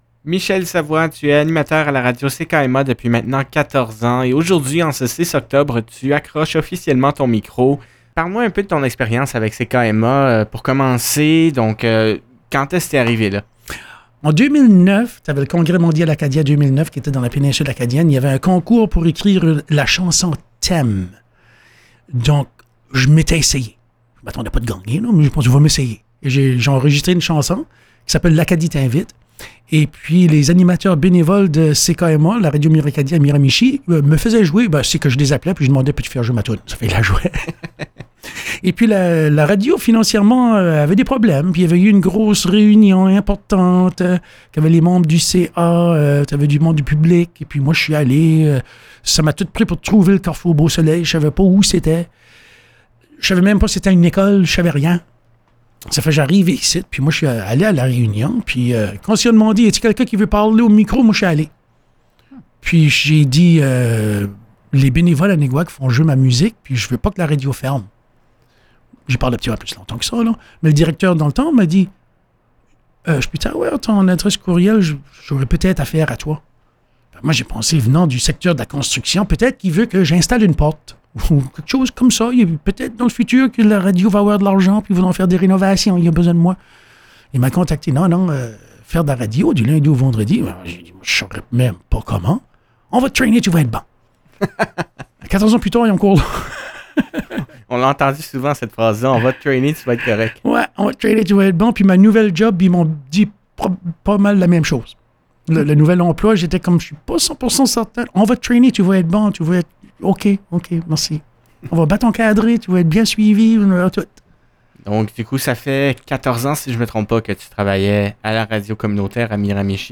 Entrevue_001_mono-1.mp3